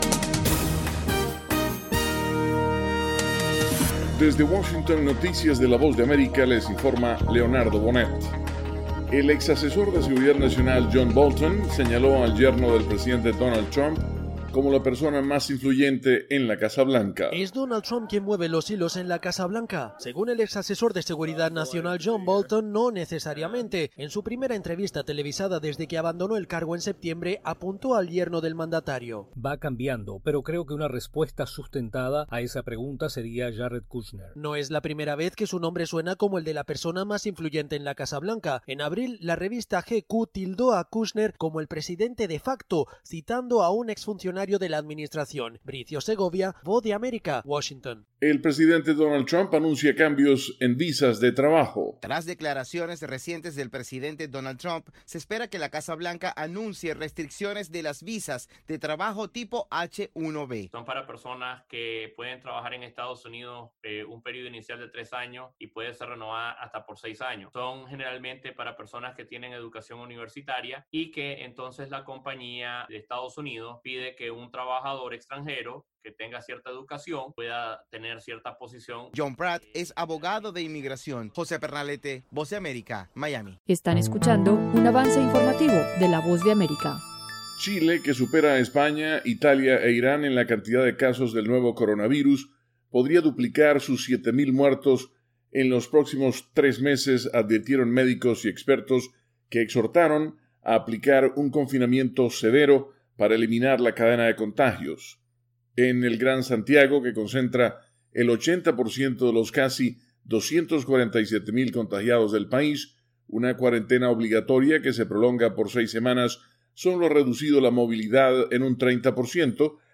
Segmento informativo de 3 minutos con noticias de Estados Unidos y el resto del mundo.